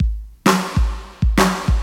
• 131 Bpm Drum Groove G# Key.wav
Free breakbeat sample - kick tuned to the G# note. Loudest frequency: 1357Hz
131-bpm-drum-groove-g-sharp-key-BcW.wav